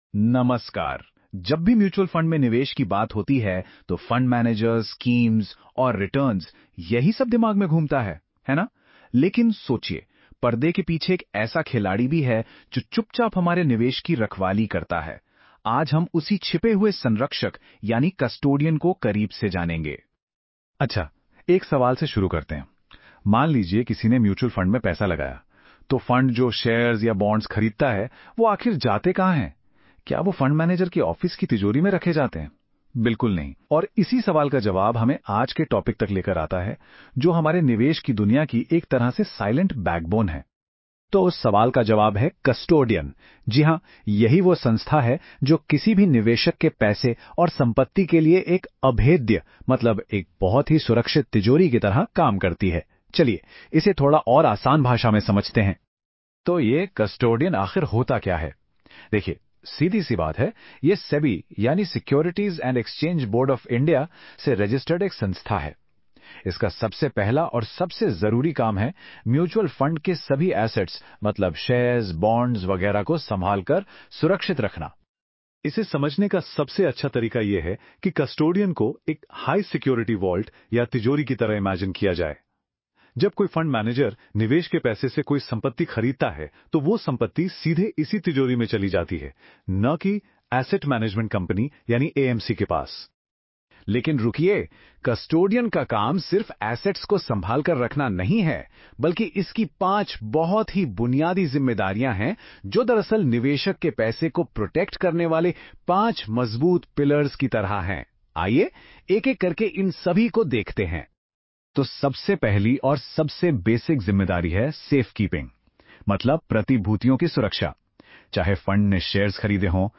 Before you start reading, listen to a short Hindi audio overview of this post — it gives you a quick idea about the topic in just a minute.